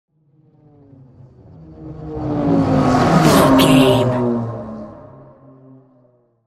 Pass by vehicle engine deep
Sound Effects
pass by
car